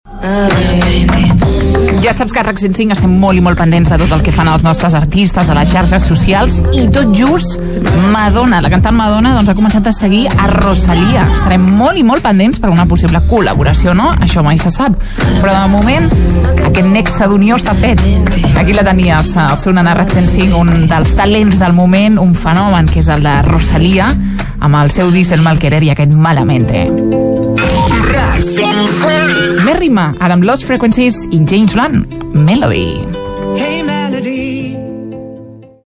Presentació de temes musicals
FM